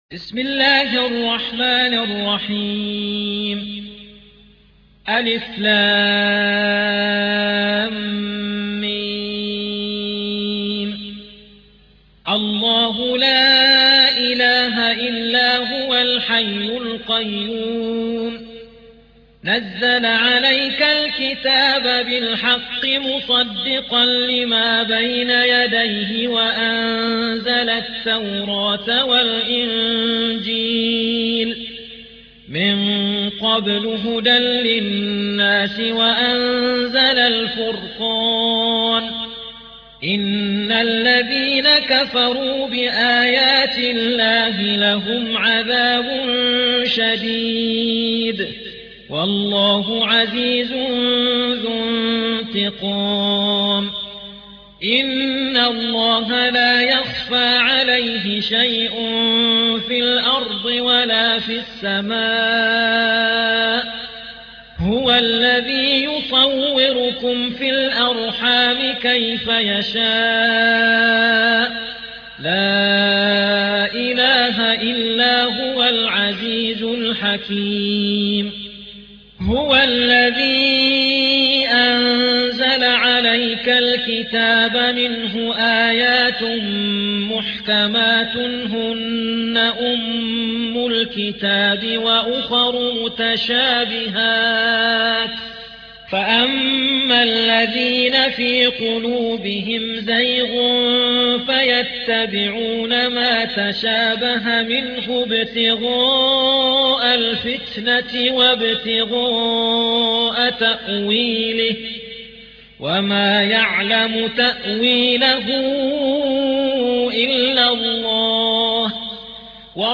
3. سورة آل عمران / القارئ